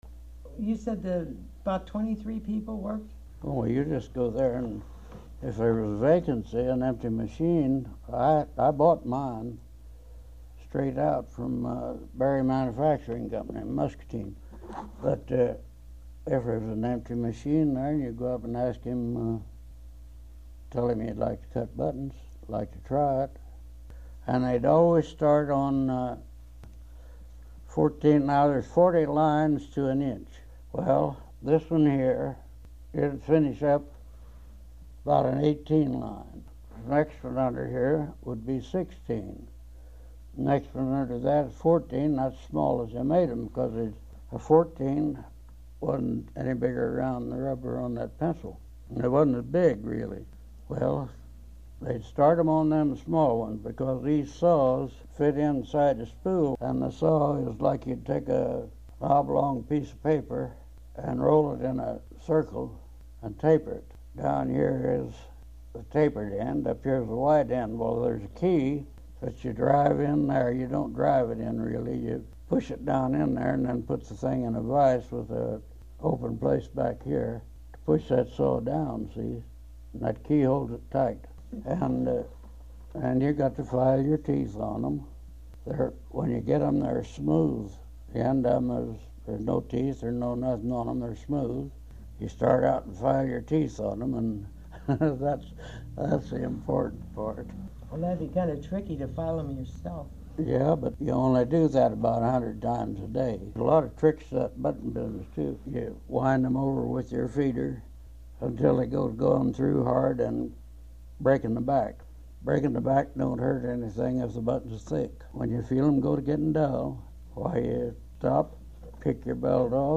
HTR Oral History, 07/18/1